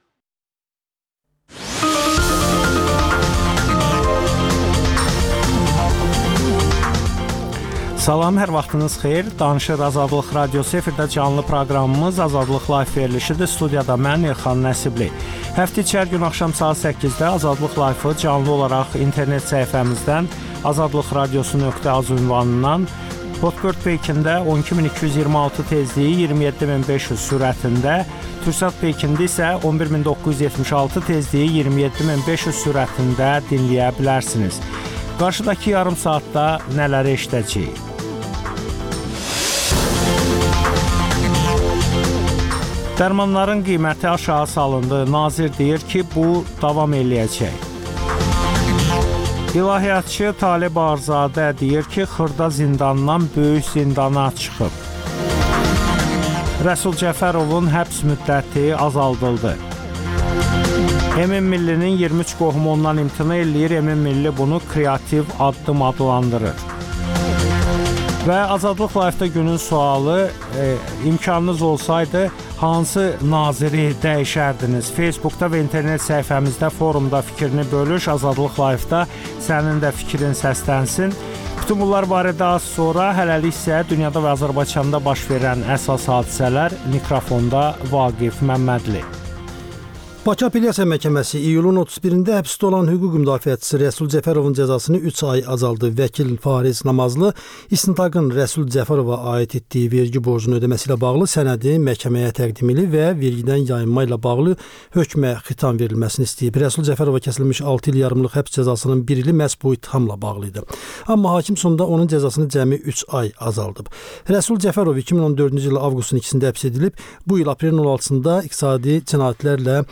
Azərbaycanda və dünyda baş verən hadisələrin ətraflı analizi, təhlillər, müsahibələr.